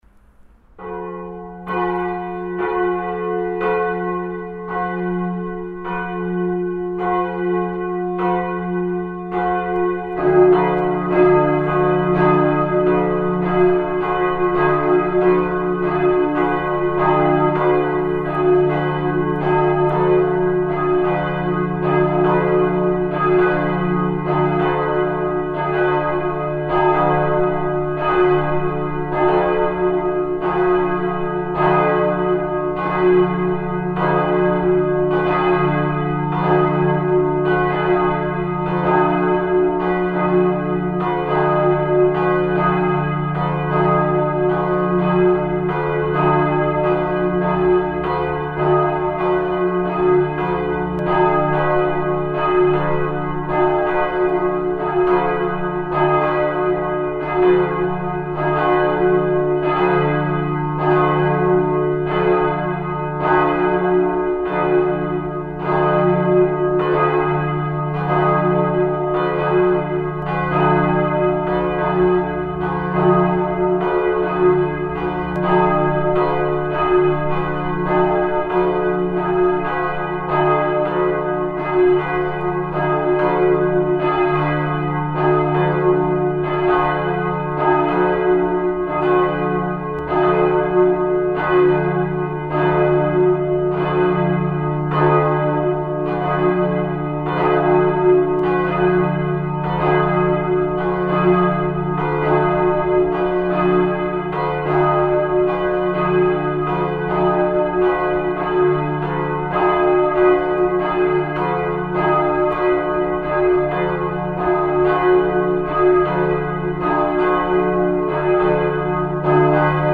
Da St. Lorenz vier verschiedene Glocken besitzt (nämlich mit den Nominalen, also gehörten Tönen, d1, f1, g1 und b1), ergeben sich daraus verschiedene Läut-Kombinationsmöglichkeiten, die je nach Anlass zum Tragen kommen und die in der Läuteordnung für St. Lorenz festgelegt sind.
06-Glocken-2-1.mp3